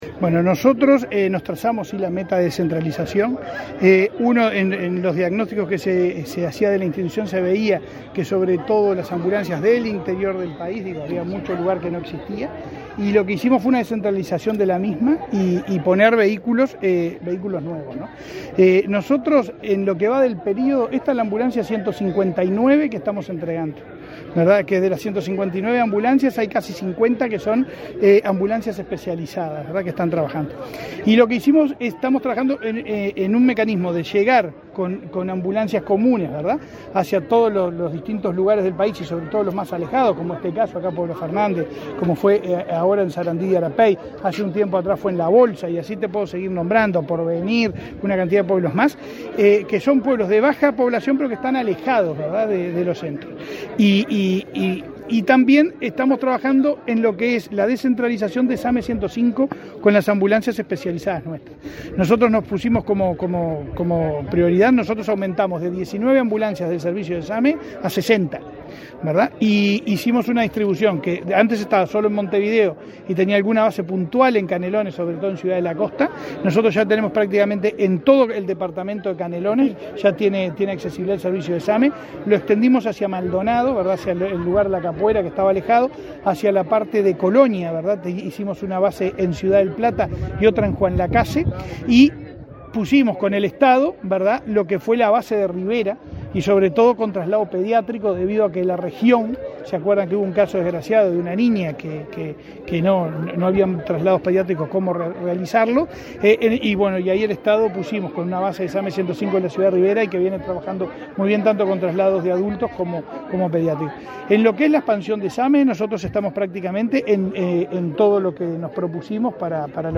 Declaraciones a la prensa del presidente de ASSE, Leonardo Cipriani
Tras participar en la entrega de ambulancias en el departamento de Salto, este 16 de agosto, el presidente de la Administración de los Servicios de
cipriani prensa.mp3